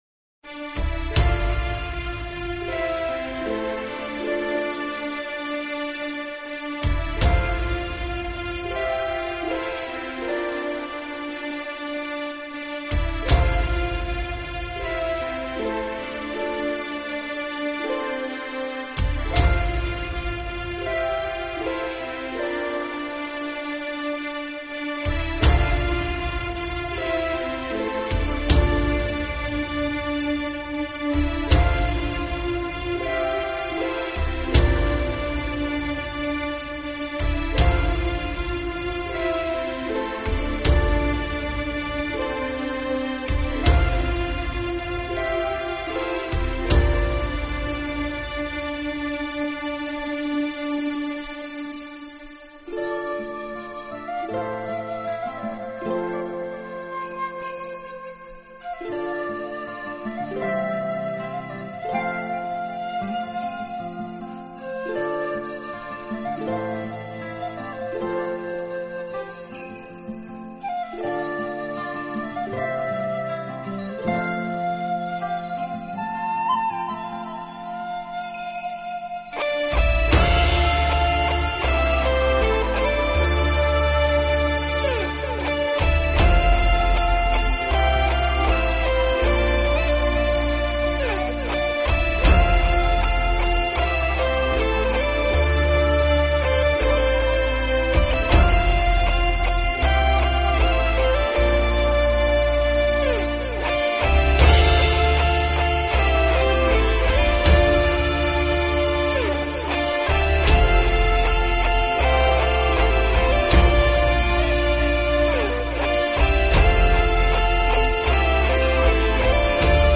专辑语言：纯音乐
整张专辑基本属于冥想风格，一如既
音乐节奏轻柔舒缓，钢琴、大提琴、笛声以及其它乐